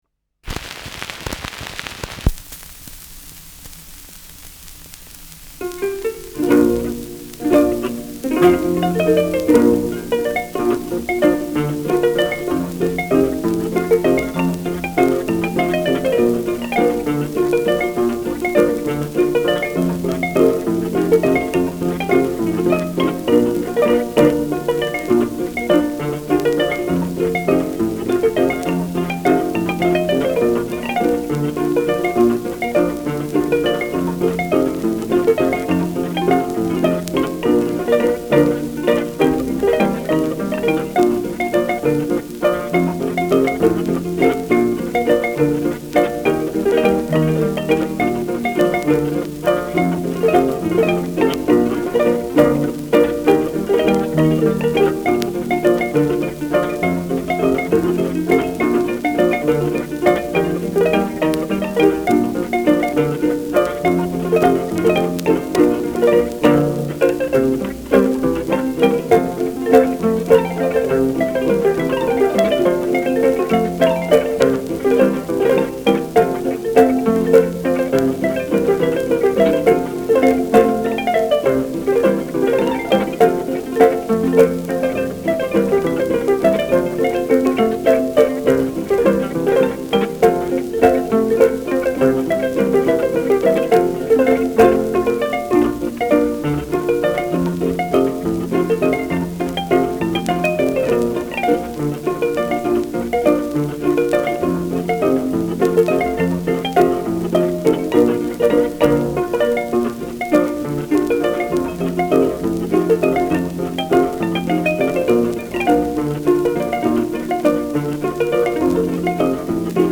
Schellackplatte
Stubenmusik* FVS-00016